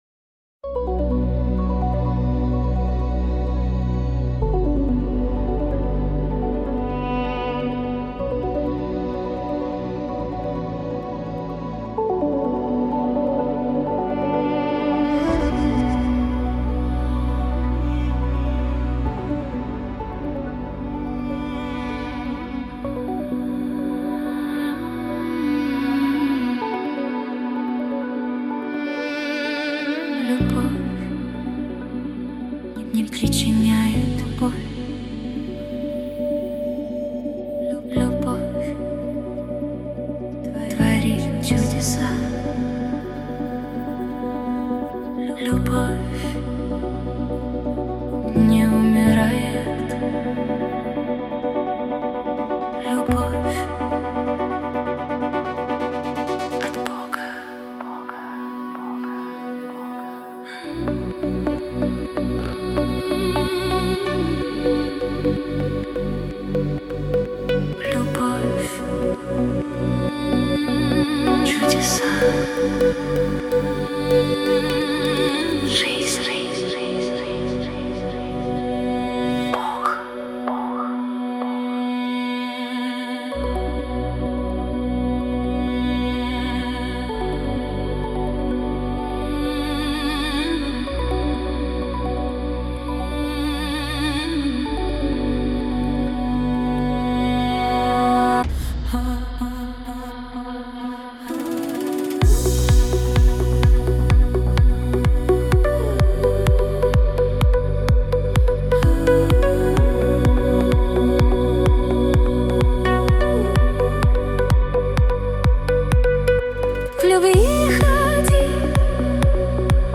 песня ai
64 просмотра 157 прослушиваний 14 скачиваний BPM: 127